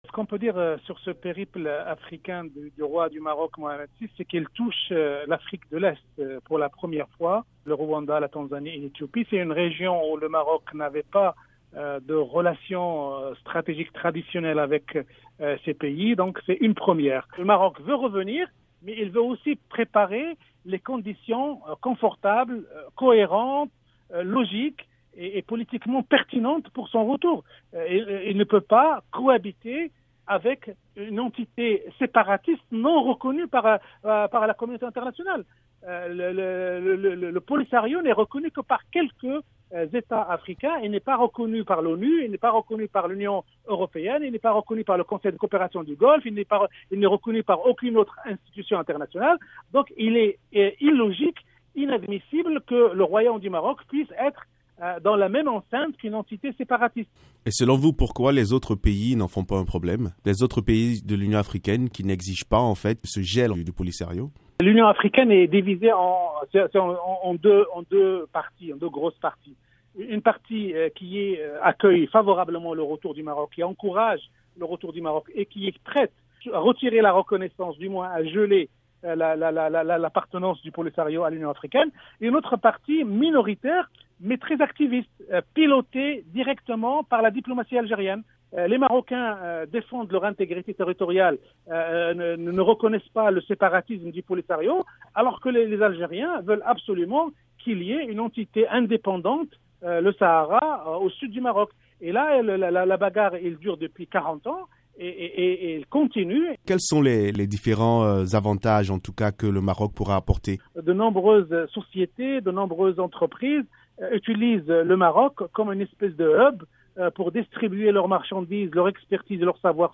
Analyse de la tournée Est-africaine du roi Mohamed VI